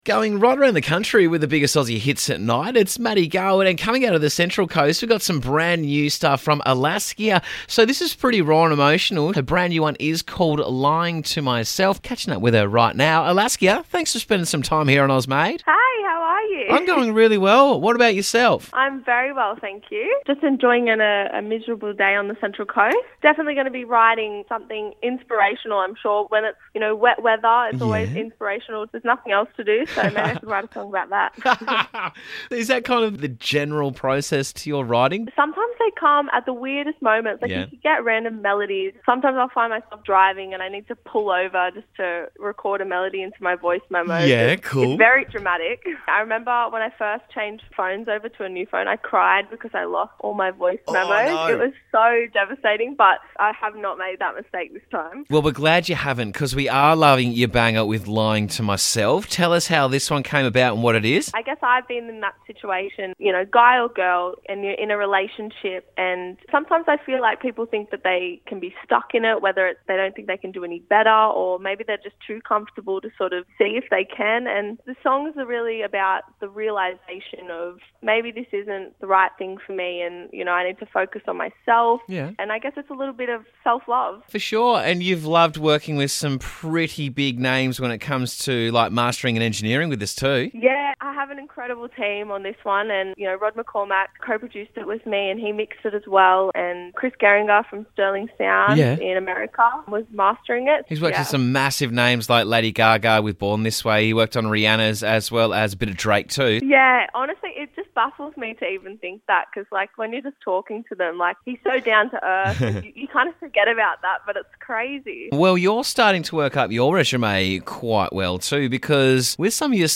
With smooth vocals that intertwine with her piano